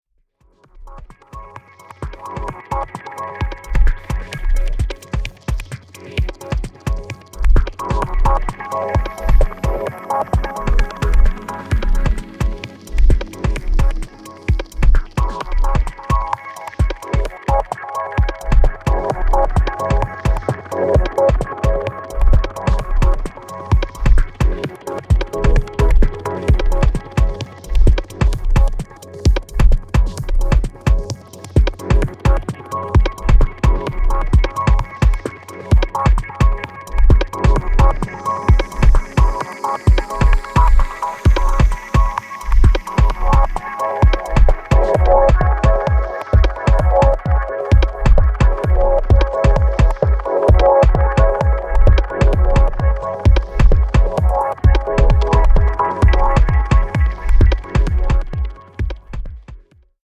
Deep House Dub Techno House